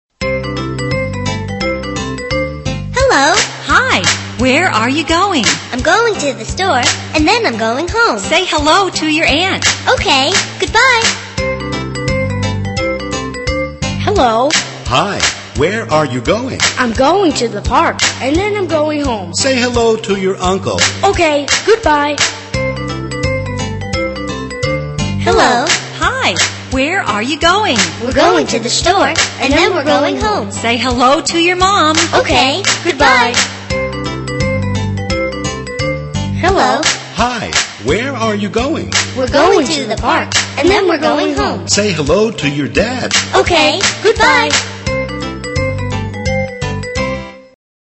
在线英语听力室英语儿歌274首 第60期:Hello!的听力文件下载,收录了274首发音地道纯正，音乐节奏活泼动人的英文儿歌，从小培养对英语的爱好，为以后萌娃学习更多的英语知识，打下坚实的基础。